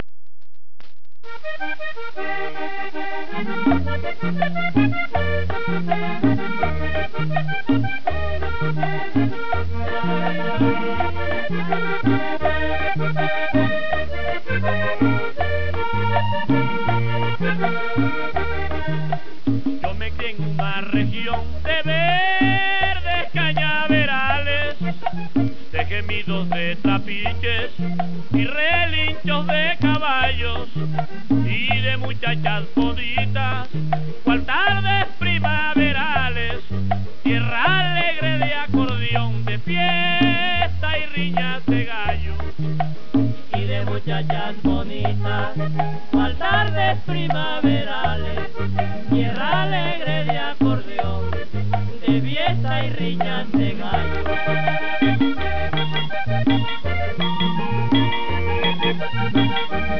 Paseo vallenato